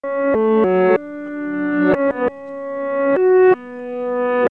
Prenons par exemple 9 notes jouées au piano (fugue en fa dièse mineur, BWV883, de J.S. Bach) numérisées sur 16 bits à 44,1 kHz
Un simple retournement de chaque note jouée, entraîne l'obtention d'une séquence sonore, sans doute encore musicale, mais où l'on ne reconnaît évidemment plus le timbre du piano